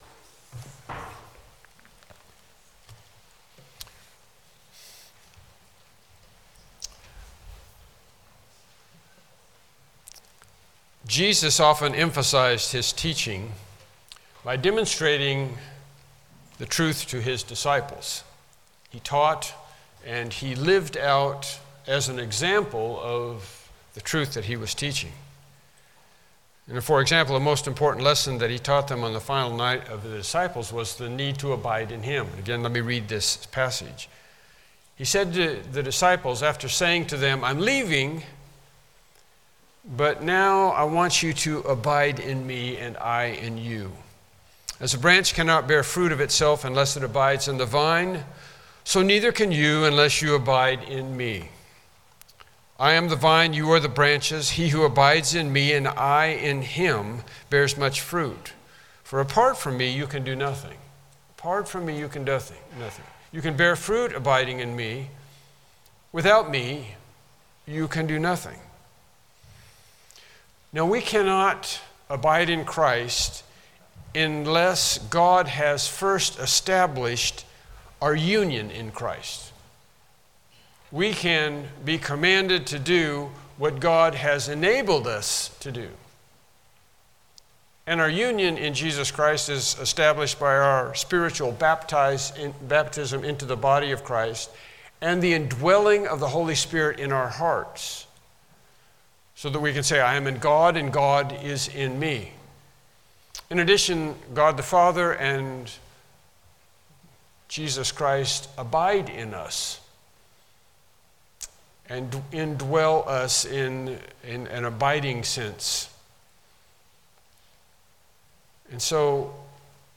Mark Passage: Mark 14:43-52 Service Type: Morning Worship Service « Lesson 12